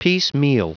Prononciation du mot piecemeal en anglais (fichier audio)
Prononciation du mot : piecemeal